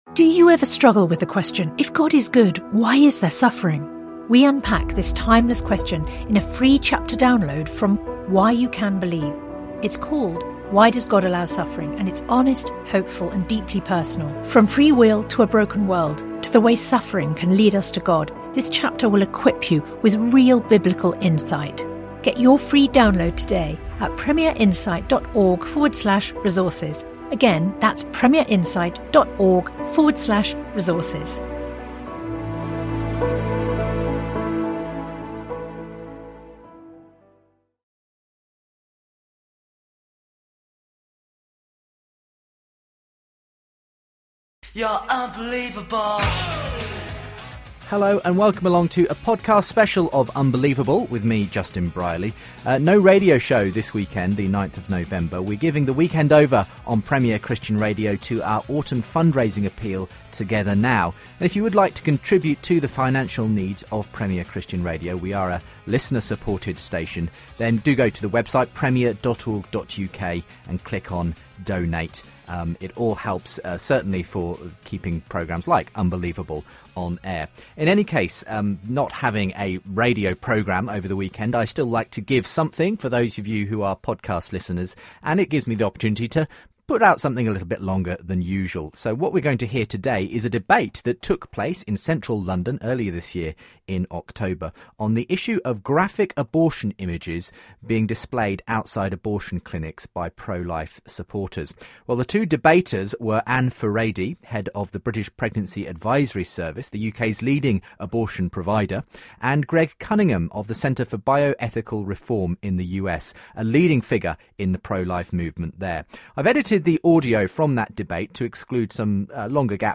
The debate took place in London and was organised by Christian Concern.